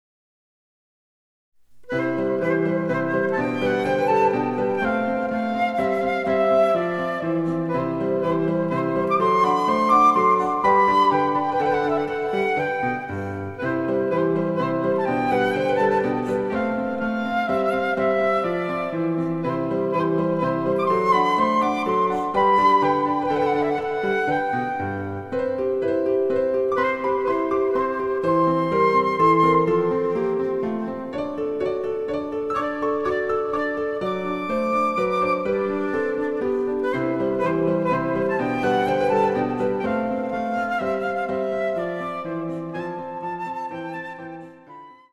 ★フルートの名曲をピアノ伴奏つきで演奏できる、「ピアノ伴奏ＣＤつき楽譜」です。